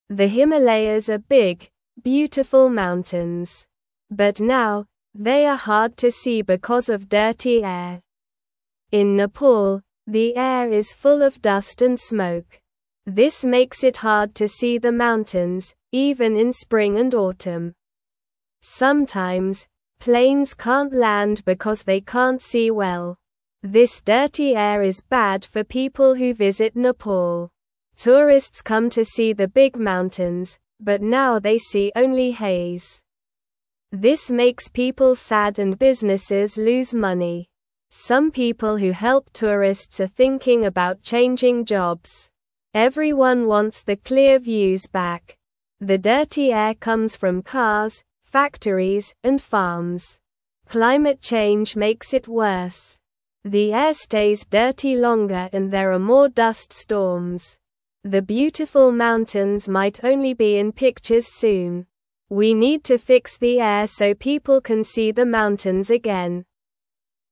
Slow
English-Newsroom-Beginner-SLOW-Reading-Dirty-Air-Hides-Beautiful-Himalayas-from-Everyone.mp3